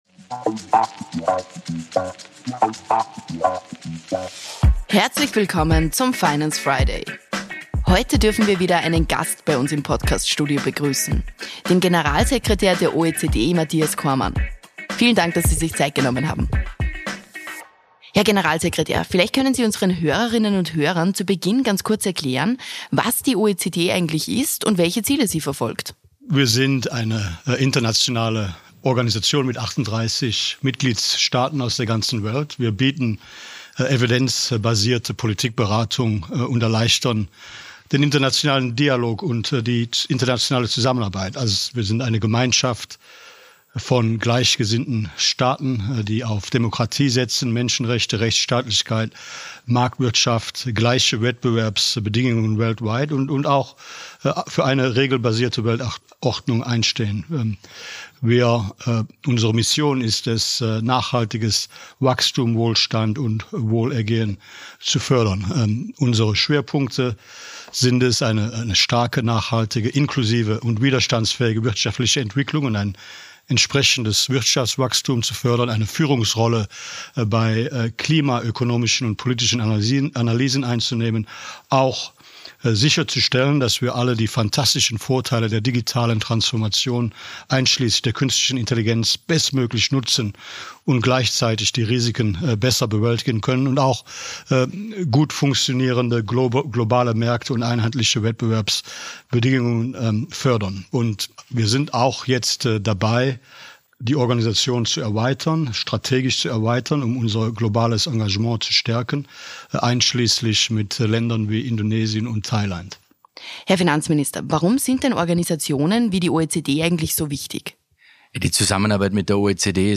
Finanzminister Magnus Brunner und Generalsekretär Cormann haben sich über den Länderbericht für Österreich unterhalten, über globale Herausforderungen und darüber, welche Schwerpunkte der OECD-Generalsekretär künftig setzen wird.